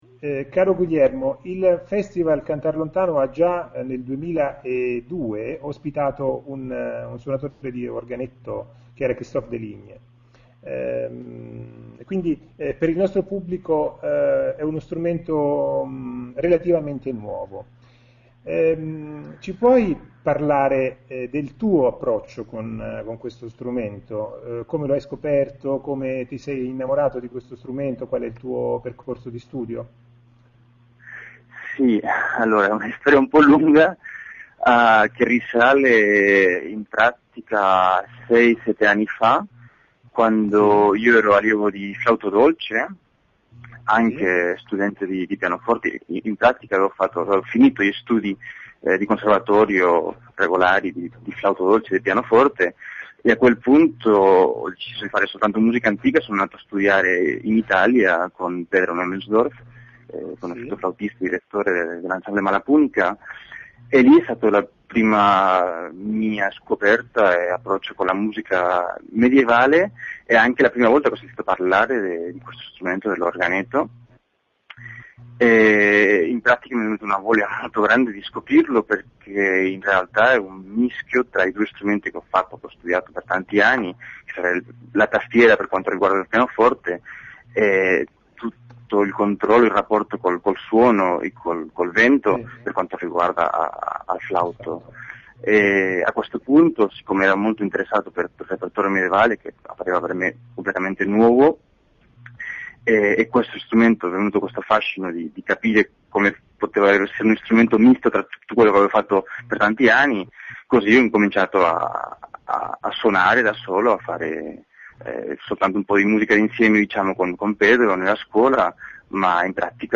Le interviste agli artisti 2007
Ecco le interviste in formato mp3, concerto per concerto: